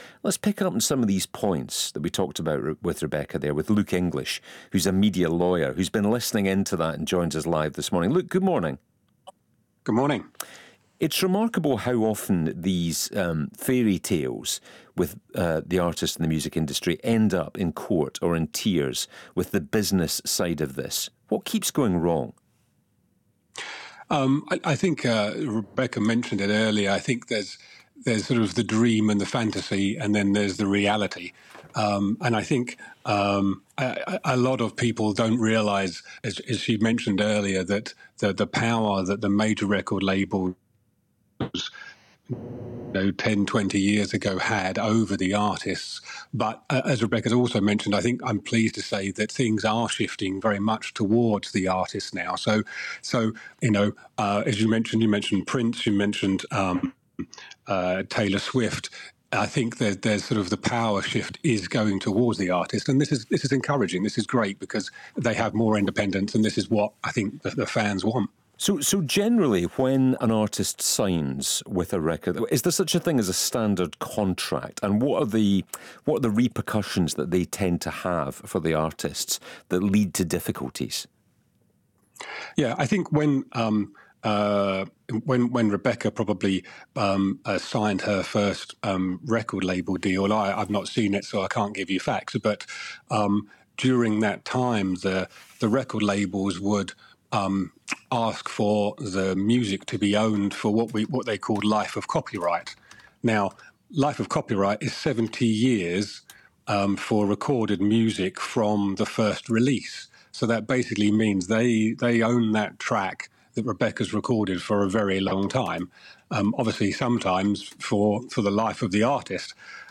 Interview with Stephen Jardine talking with Rebecca Ferguson about her new album and how the music industry has changed for the positive since she signed with The X Factor.